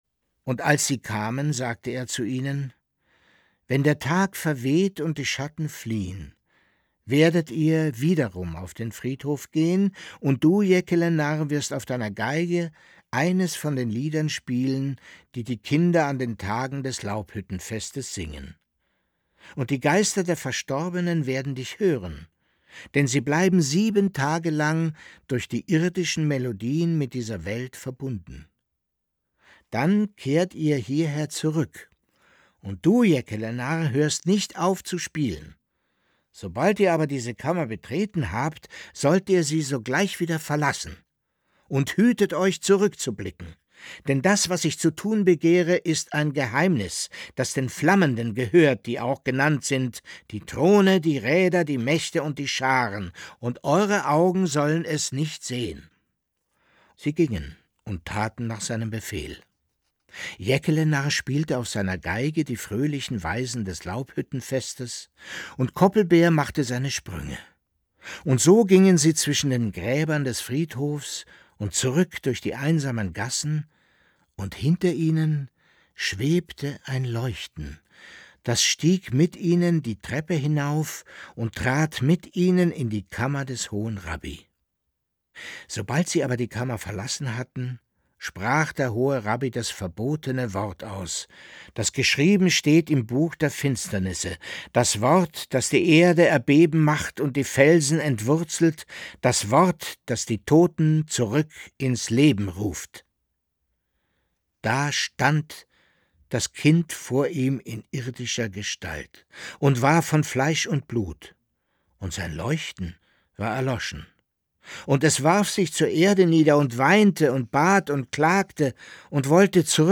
Leo Perutz: Nachts unter der steinernen Brücke (2/25) ~ Lesungen Podcast